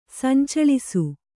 ♪ sancaḷisu